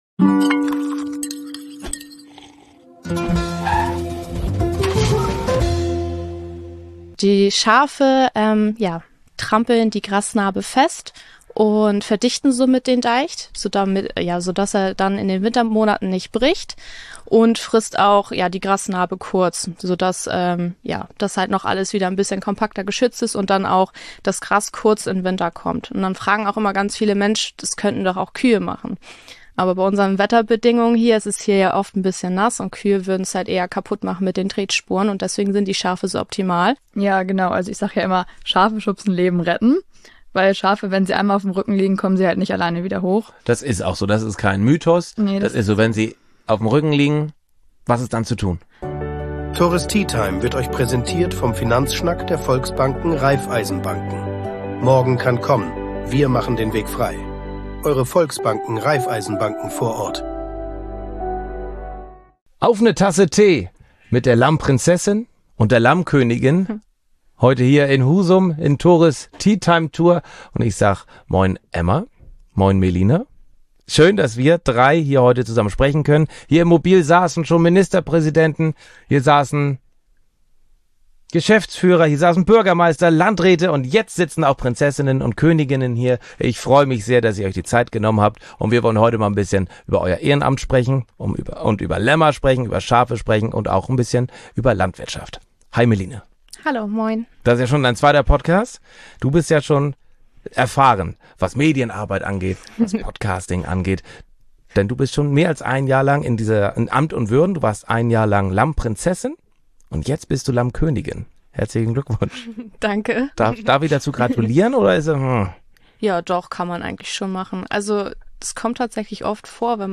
Hoher Besuch in meinem Podcastmobil: zwei nordfriesische Majestäten waren zu Gast und sprachen über ihre neuen Ämter.